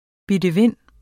Udtale [ bidəˈvenˀ ]